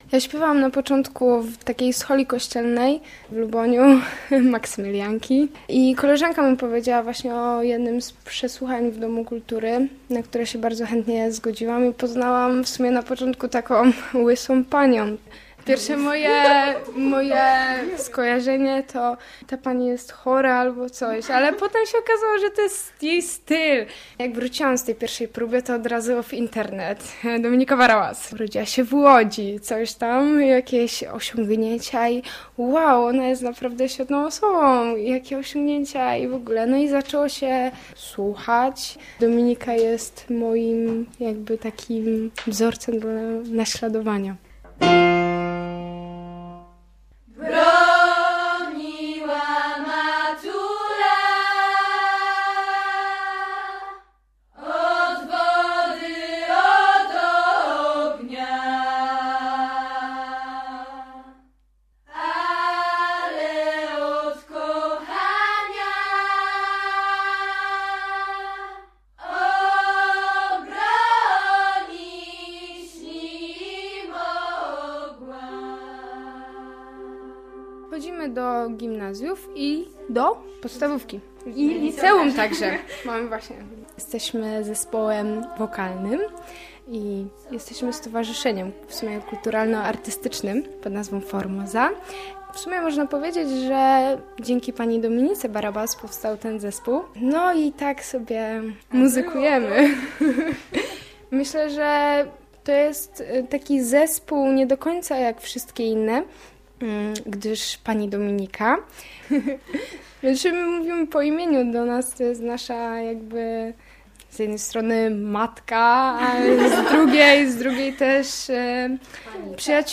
Proszę mnie nie bić więcej - reportaż